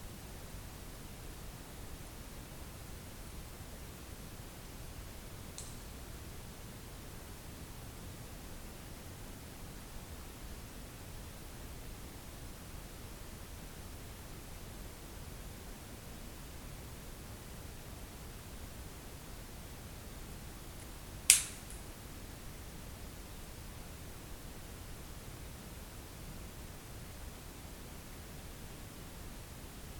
Stick snap sounds are continuous in left channel
06.59.43 very loud stick break left channel -
06.59.43 Snap and Stick Break
07.00.30 snapping continues